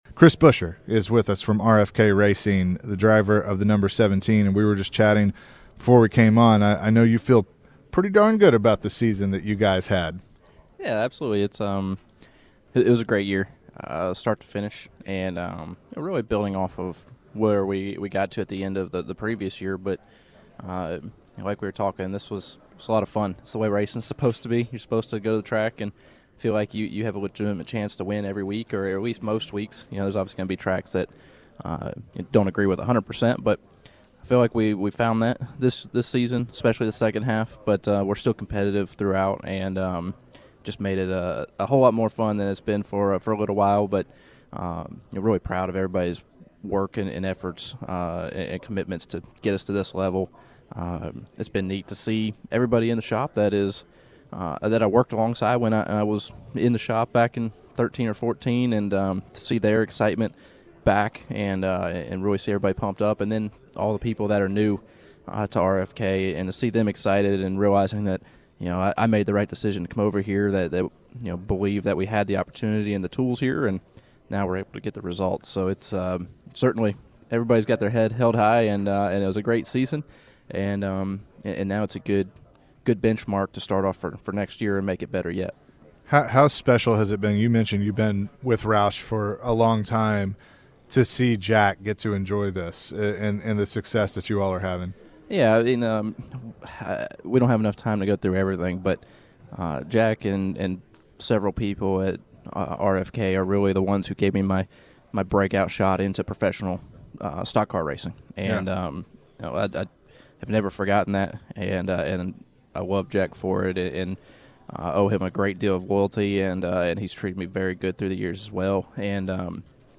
at today's NASCAR Awards celebration